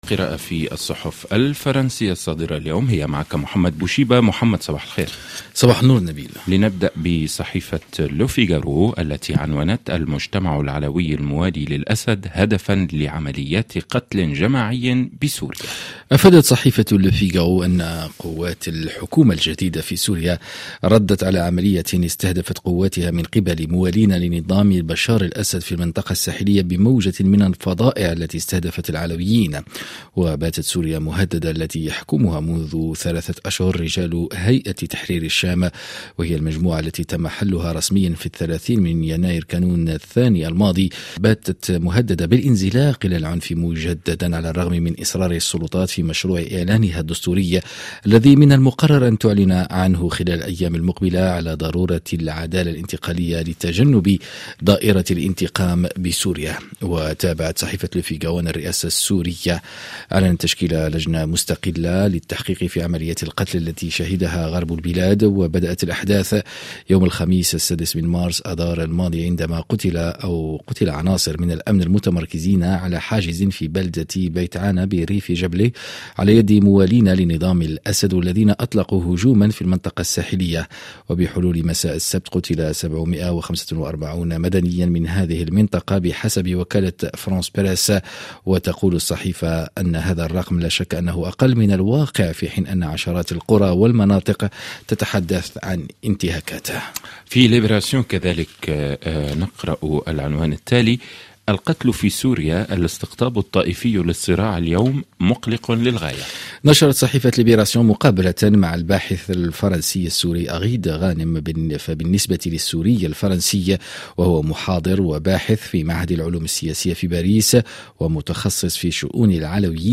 ما لم تقرؤوه في صحف الصباح تستمعون إليه عبر أثير "مونت كارلو الدولية" في عرض يومي صباحي لأهم التعليقات والتحليلات لكل قضايا الساعة في فرنسا والعالم العربي والعالم وحازت على اهتمام الصحف الفرنسية.